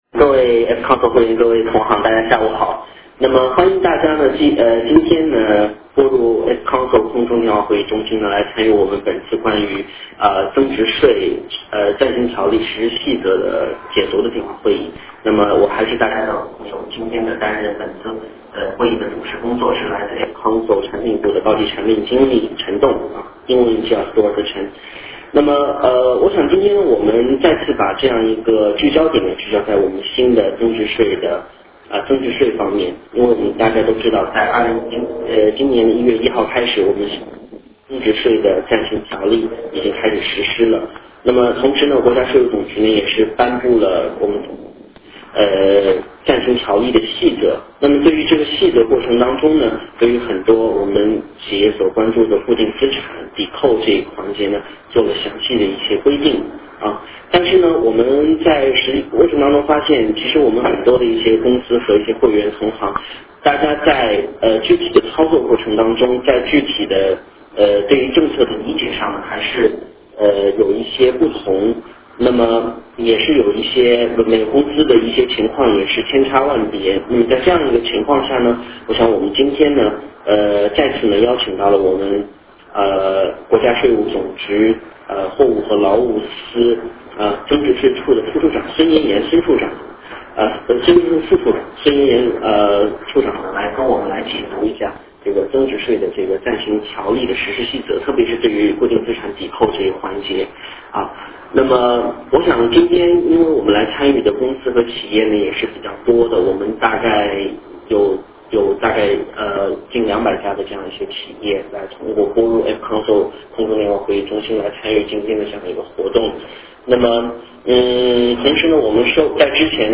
国家税务总局官员增值税暂行条例实施细则解读电话会议
聆听国家税务总局货物和劳务司增值税处处长就增值税暂行条例实施细则做的权威解读，了解最终政策内容，探究实际操作方法，并在互动中解答您的顾虑！ 活 动 安排： 特邀国家税务总局货物和劳务司增值税处处长进行政策解析，并与广大与会代表进行互动交流。